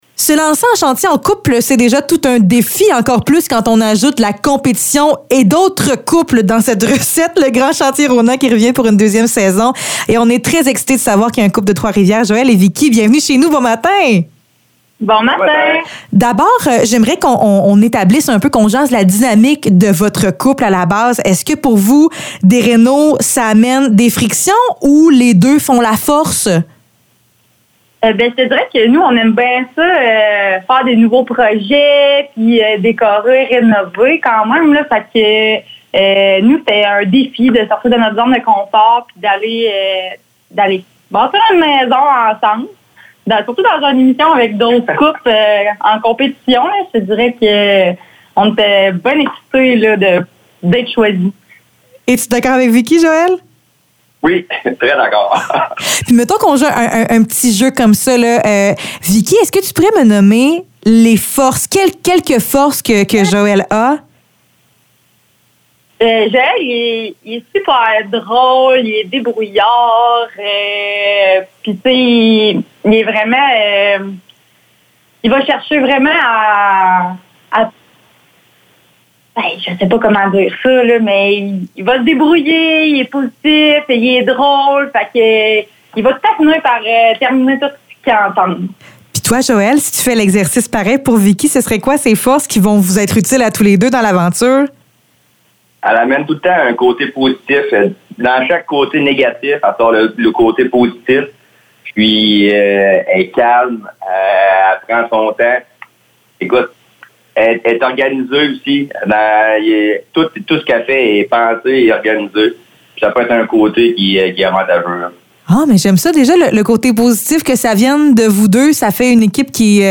Entrevue pour le Grand Chantier Rona Saison 2